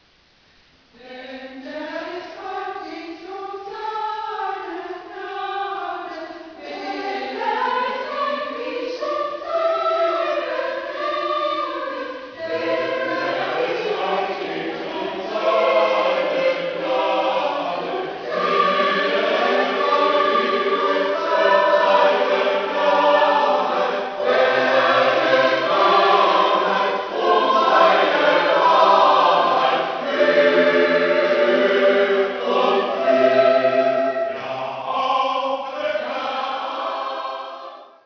so klingt der Liederkranz: